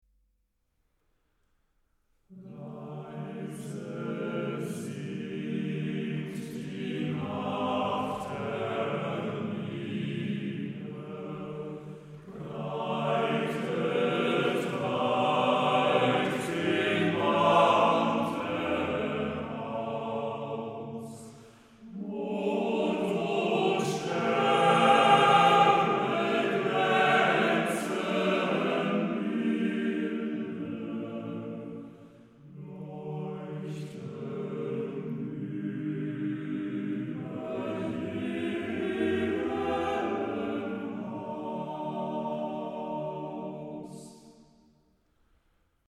DER MÄNNERCHOR
Männerchöre der Romantik